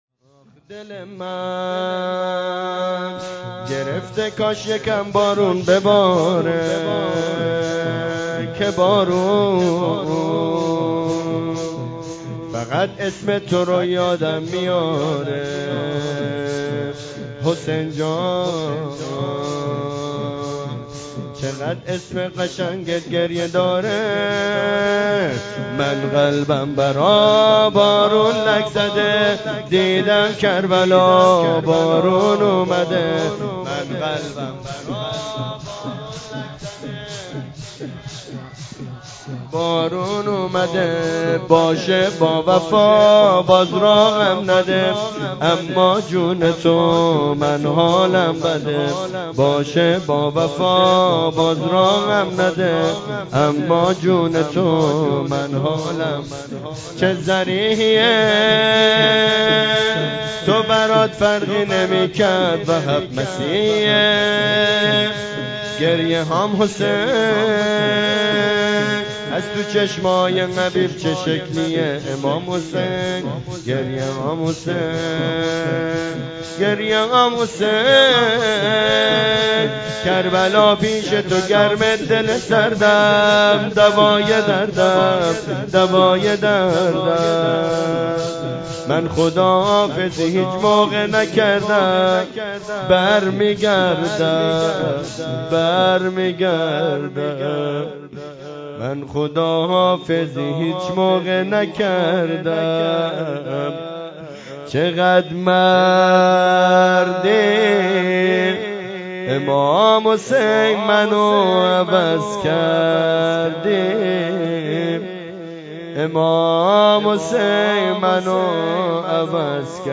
شور پایانی